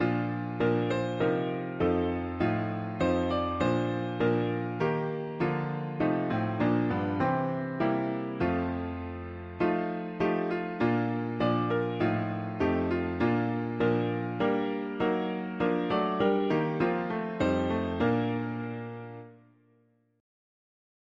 Key: B-flat major Meter: 87.87